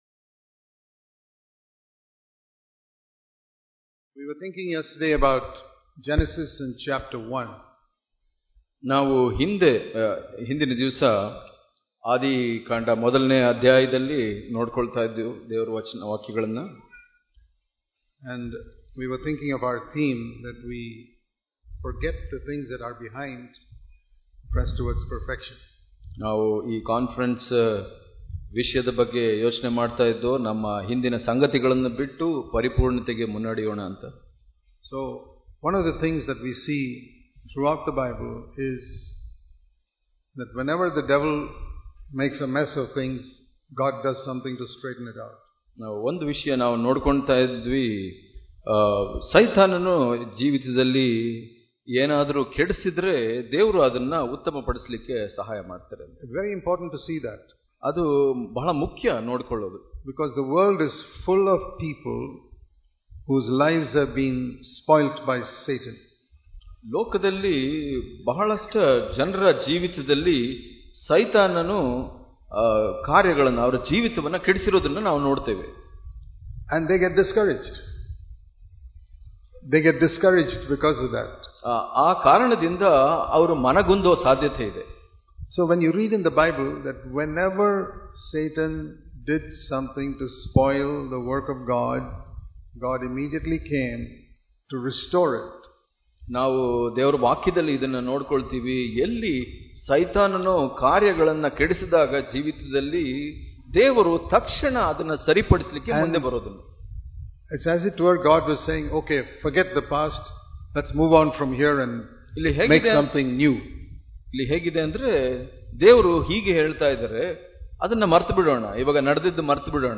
Hubli Conference 2018